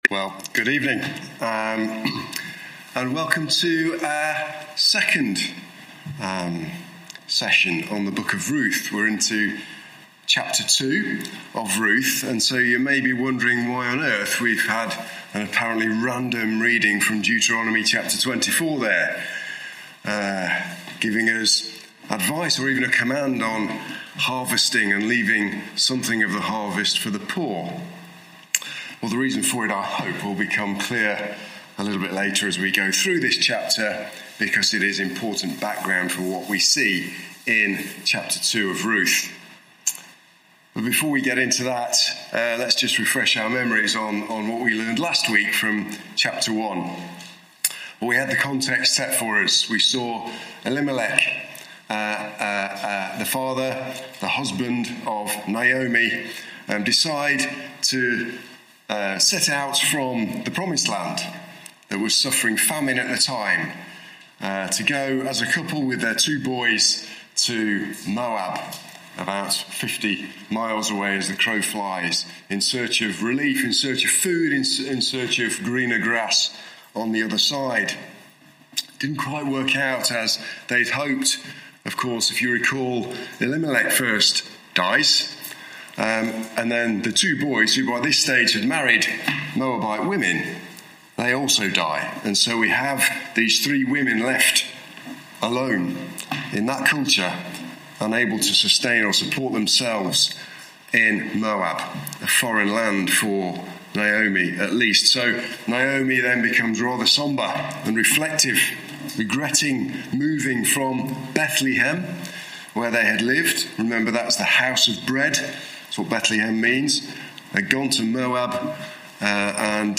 Sunday evening studies